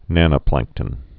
(nănə-plăngktən)